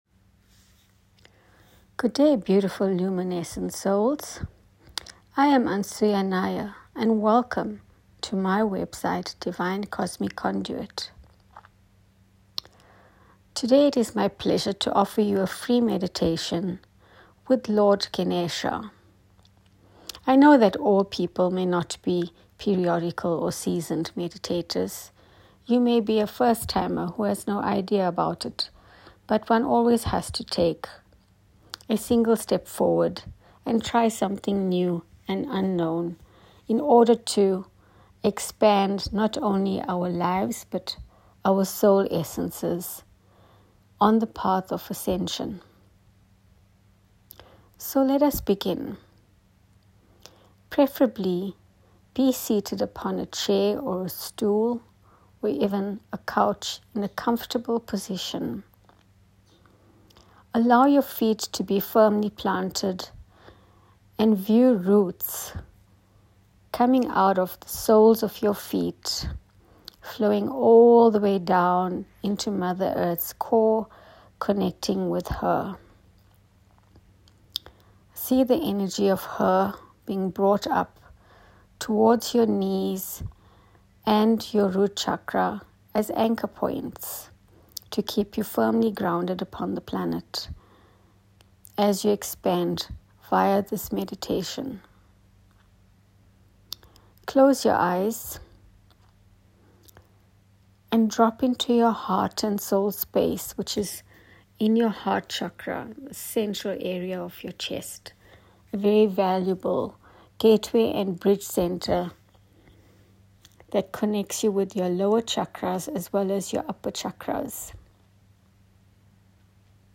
GANESHA/GANAPATHIYE MEDITATION
This basic bija sound mantra meditation will initiate you into either a budding or enhanced relationship with him.